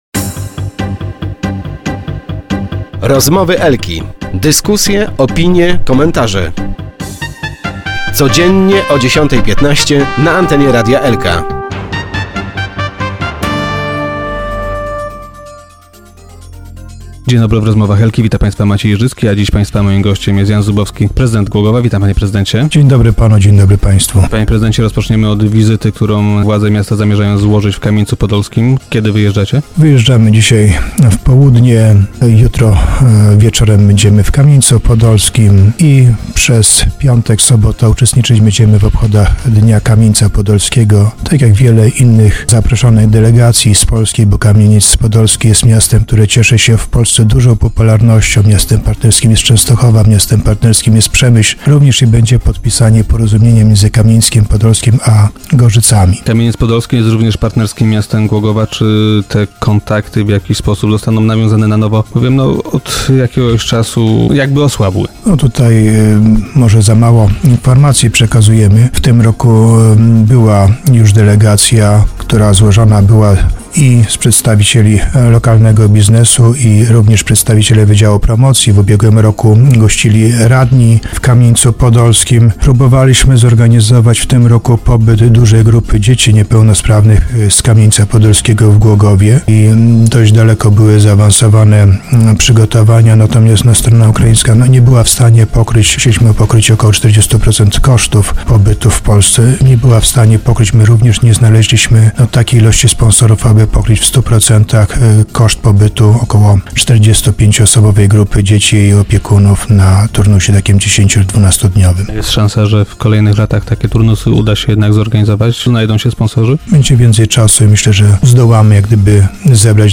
- Zapraszam głogowian na debatę, która odbędzie z tej okazji się w Domu Uzdrowienia Chorych - mówi prezydent Jan Zubowski, który był gościem Rozmów Elki.